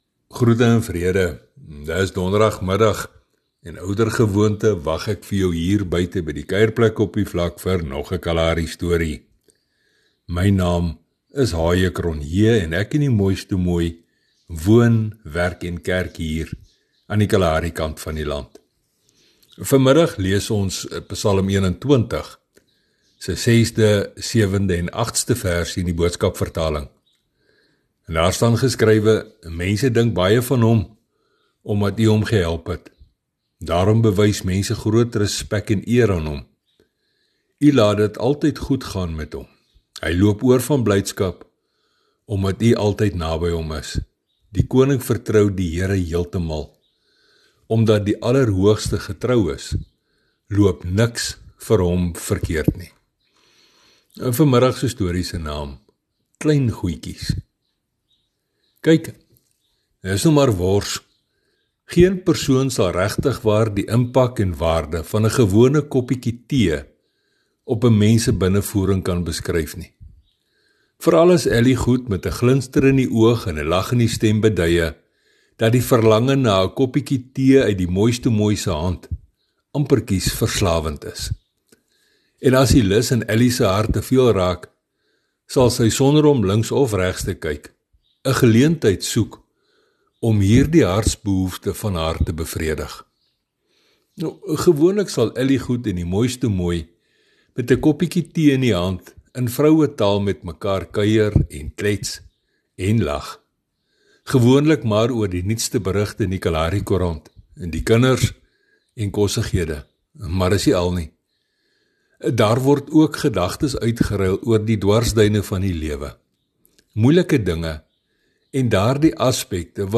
Hy vertel vandag vir ons nog een van sy Kalaharistories. Sy getuienis verhale het 'n geestelike boodskap, maar word vertel in daardie unieke styl wat mens slegs daar op die kaal vlaktes kan optel.